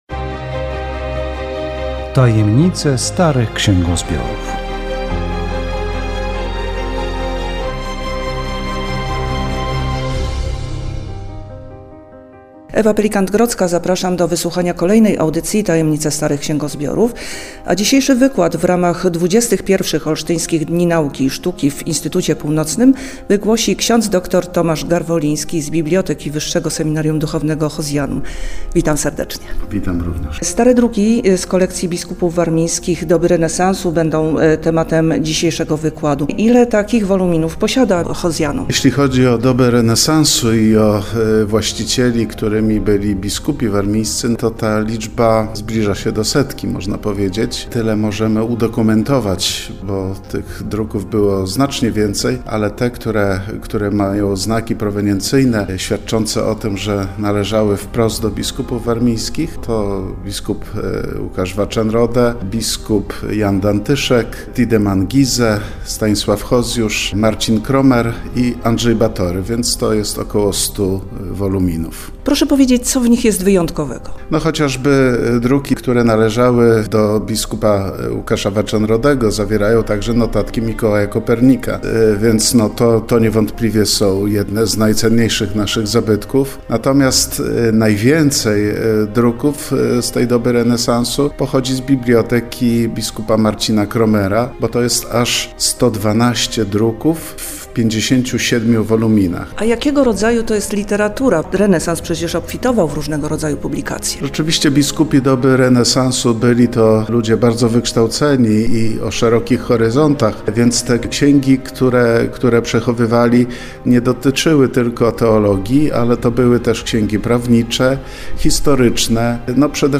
Audycja radiowa "Tajemnice starych księgozbiorów